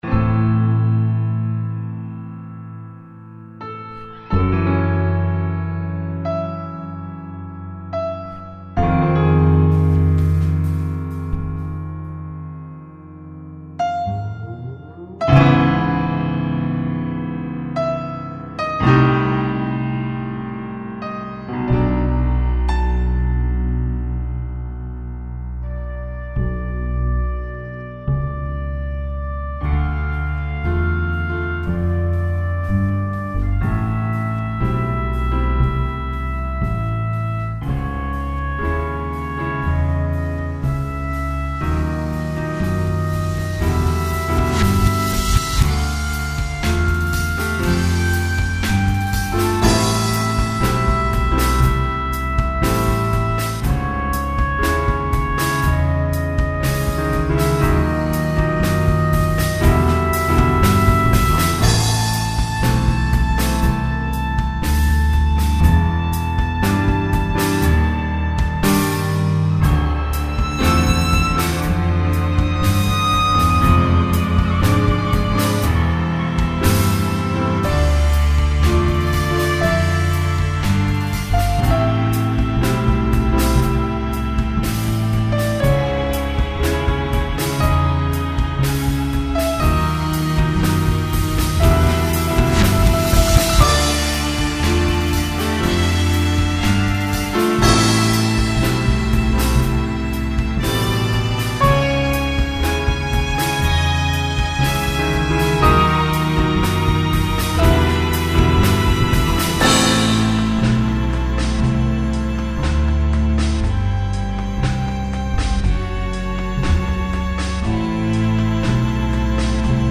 A more calm setting provides the scene for this track.